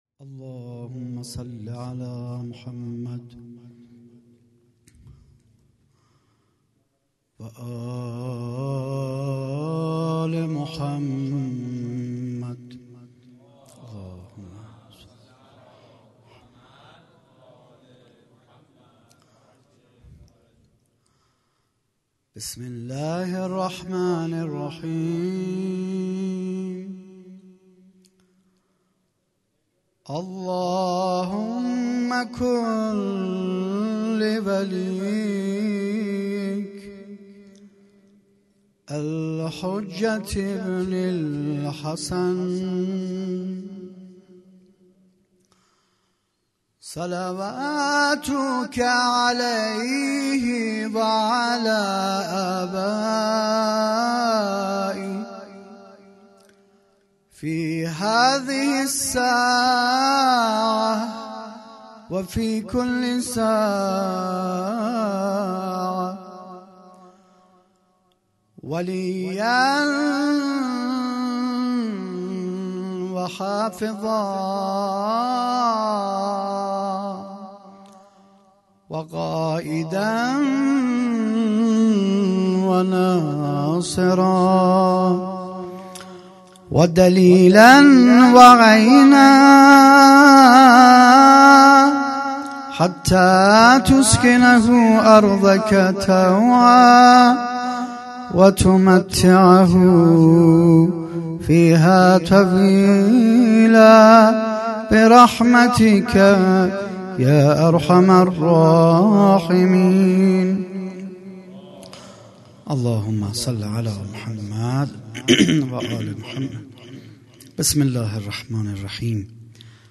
هیئت دانشجویی فاطمیون دانشگاه یزد
سخنرانی